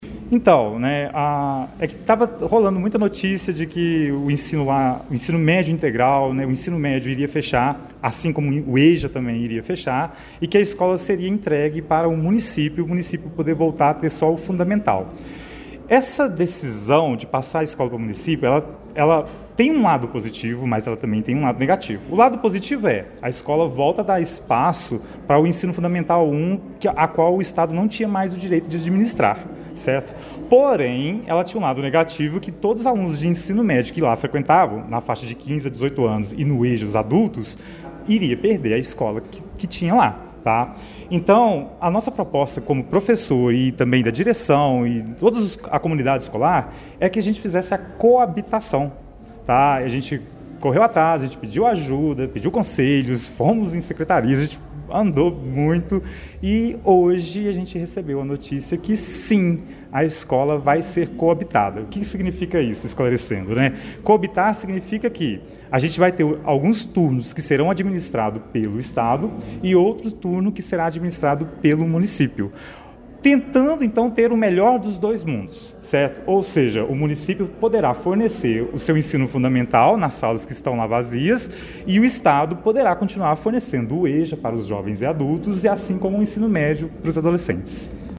O assunto também foi pauta da reunião da Câmara Municipal de Pará de Minas realizada na noite de ontem, 16 de outubro, e acompanhada pelo Portal GRNEWS, com participação de integrantes da comunidade escolar.